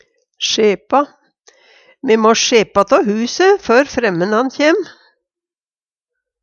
DIALEKTORD PÅ NORMERT NORSK sjepa rydde, få orden på Infinitiv Presens Preteritum Perfektum sjepa sjepa sjepa sjepa Eksempel på bruk Me må sjepa tå huse før fremmenan kjem.